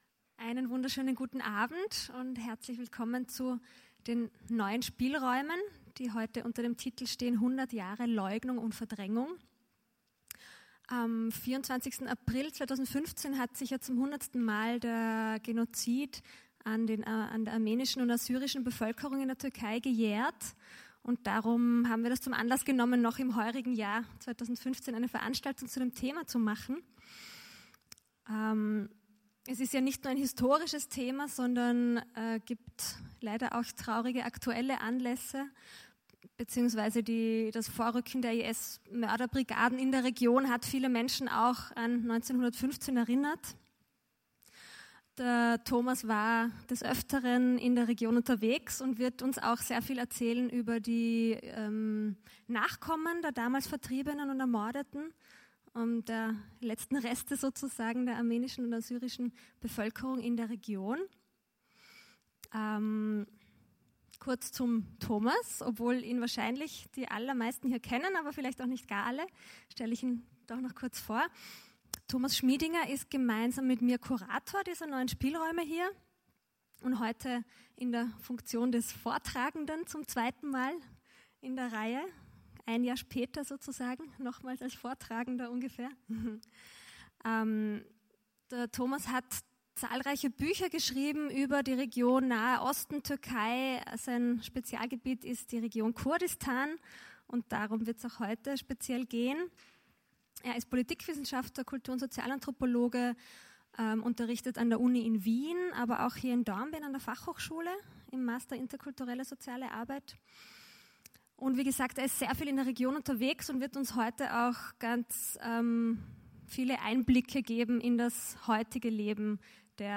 Hier könnt ihr die Diskussion als mp3 downloaden bzw. als Stream direkt anhören.